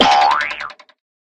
BP_14_SFX_Rope_Launch.ogg